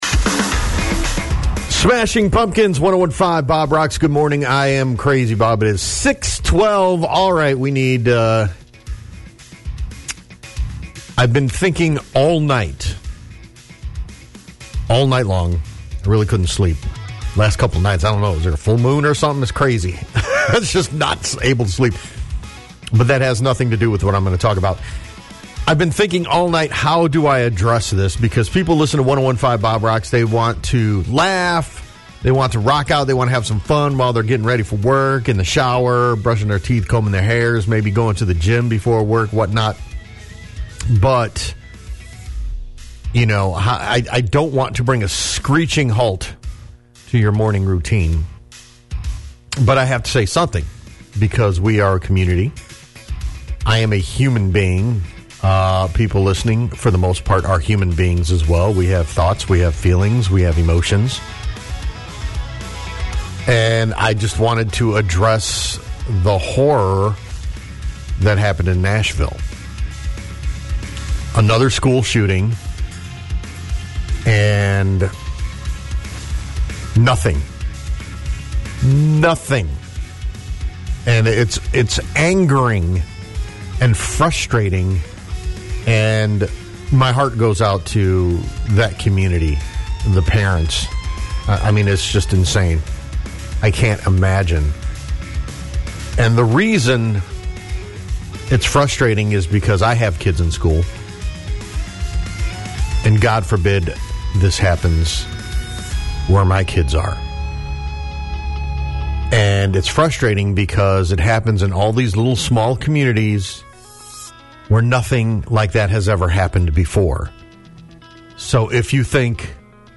This was not planned or scripted (obviously), but just being honest.